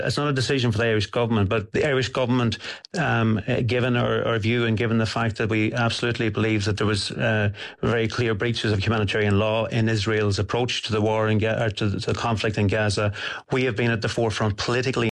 Minister Charlie McConalogue, says the government has been active politically, in addressing Israel’s conduct in the conflict in Gaza: